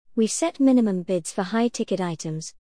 ▶アメリカ英語 (閉鎖のみ)
▶イギリス英語 (閉鎖＋リリース)